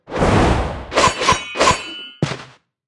Media:Sfx_Anim_Ultimate_Barbarian.wav 动作音效 anim 在广场点击初级、经典、高手、顶尖和终极形态或者查看其技能时触发动作的音效
Sfx_Anim_Ultimate_Barbarian.wav